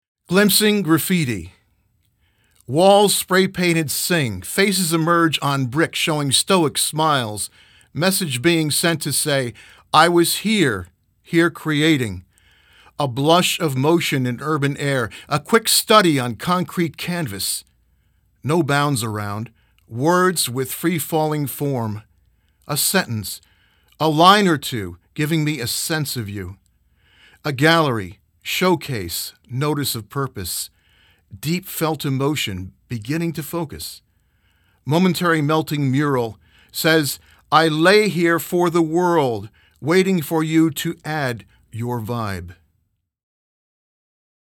Click here for a reading by the poet